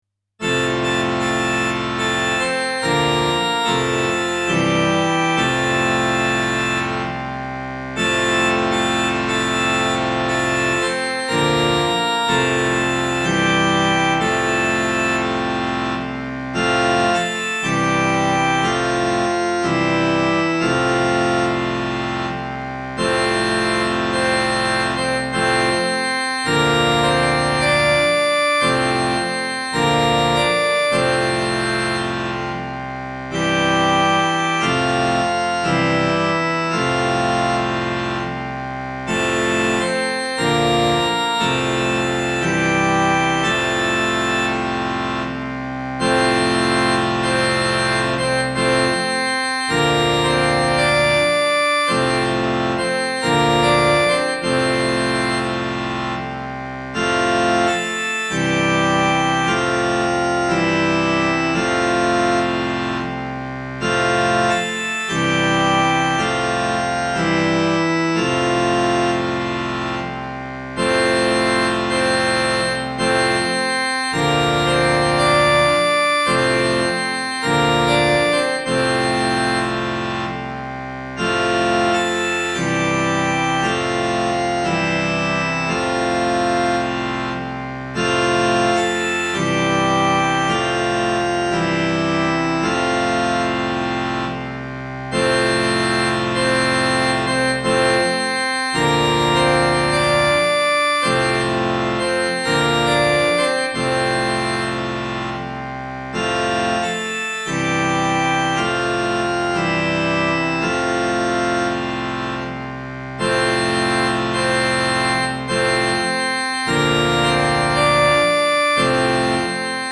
INSTRUMENTAL
Grabaciones hechas en la Ermita durante la celebración de las Novenas del año 2019/2022.